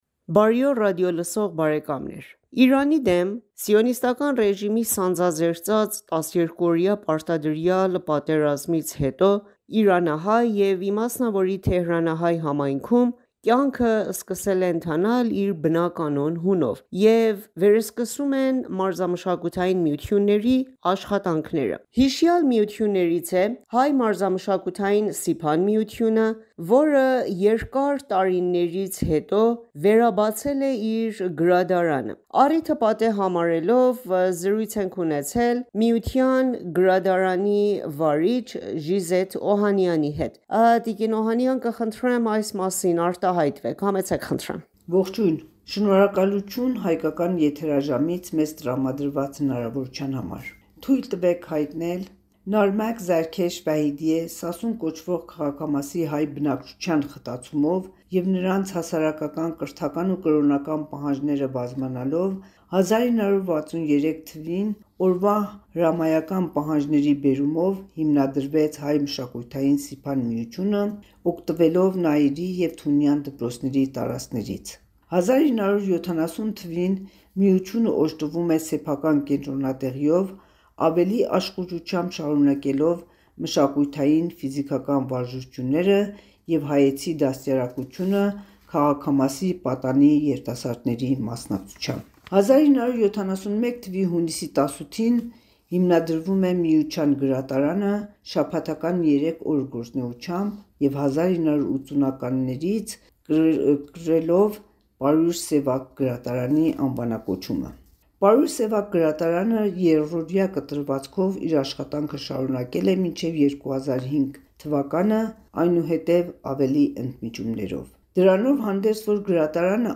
հարցազրույց